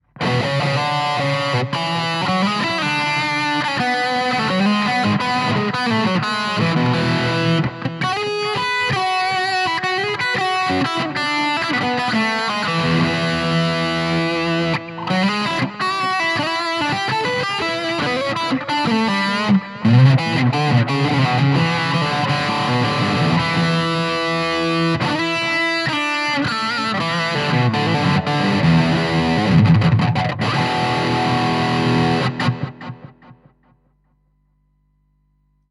Wer die Originalverstärker kennt, hört, dass die Klänge sehr ordentlich gemodelt werden und die typische Marshall-Charakteristik eindeutig erkennbar ist.
• Gibson Les Paul
• Shure SM57
marshall_code_100_test__plexified_lead.mp3